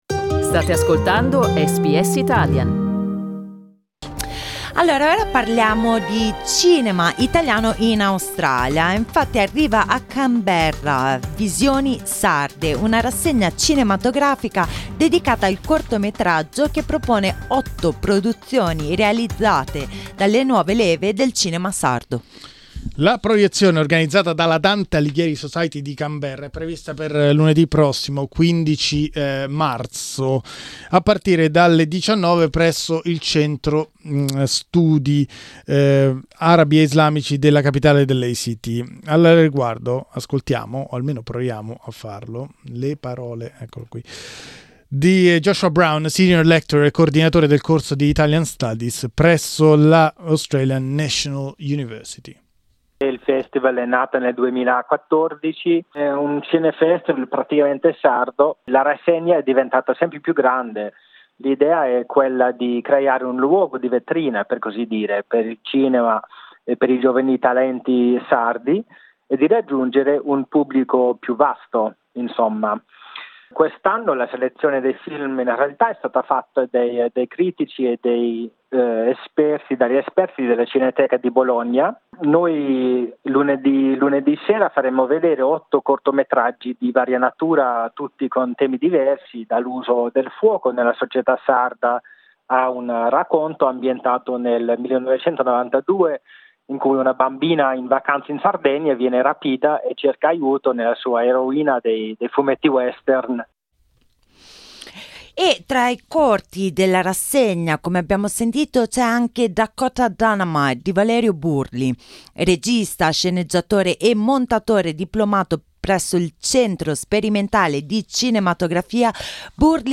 Ascolta l'intervista e alcuni estratti dai trailer: